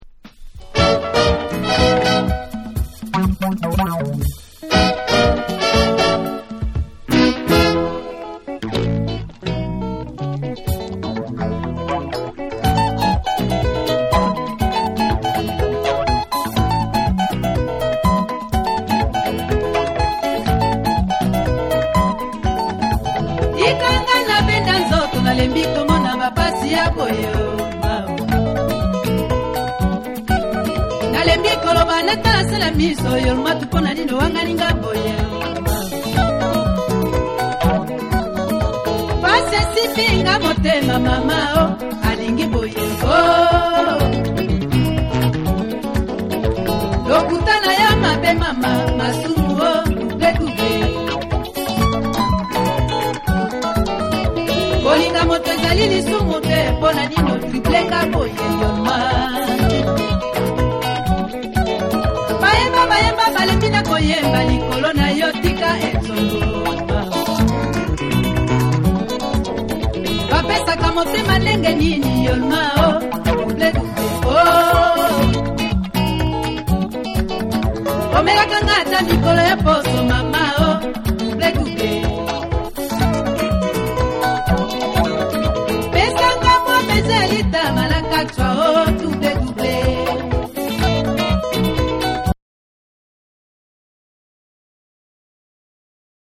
ザイール出身のリンガラ・ポップ・シンガー
程よいエコーがトロピカルな雰囲気を演出した軽快でキャッチーなリンガラ・ポップを披露した彼の代表作である1。
WORLD / AFRICA